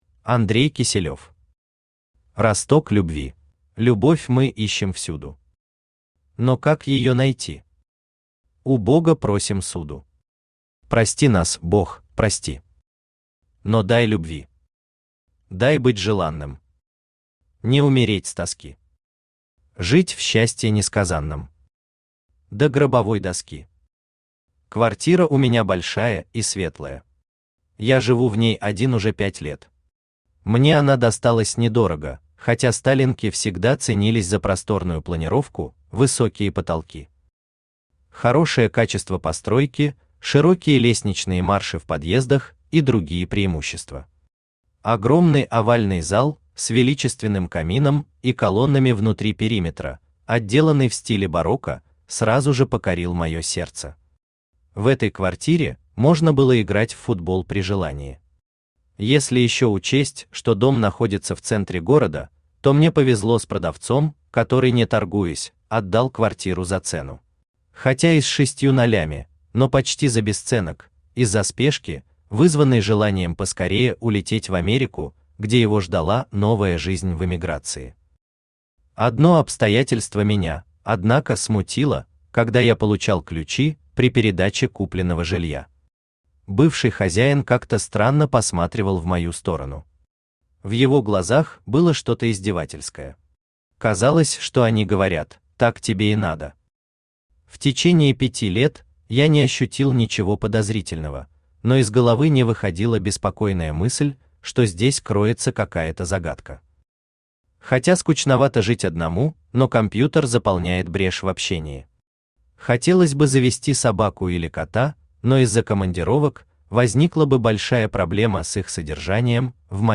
Аудиокнига Росток любви | Библиотека аудиокниг
Aудиокнига Росток любви Автор Андрей Егорович Киселев Читает аудиокнигу Авточтец ЛитРес.